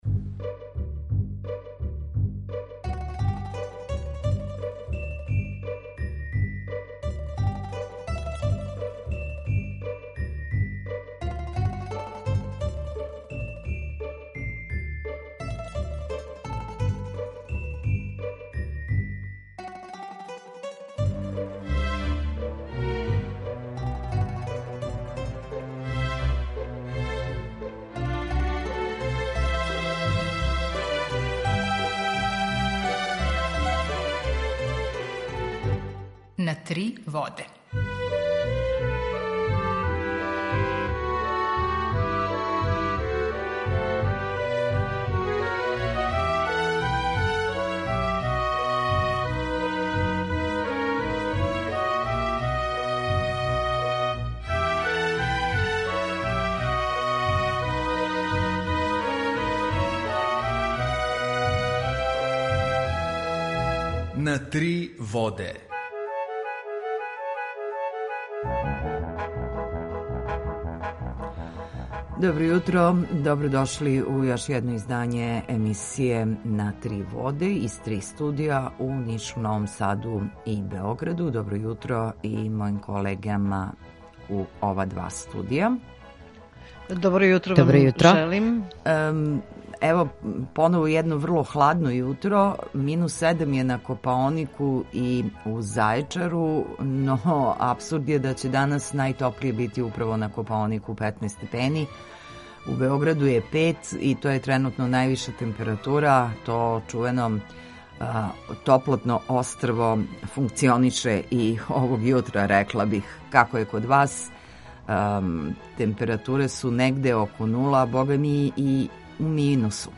Емисију реализујемо заједно са Радијом Републике Српске у Бањалуци и Радијом Нови Сад
Јутарњи програм из три студија
У два сата, ту је и добра музика, другачија у односу на остале радио-станице.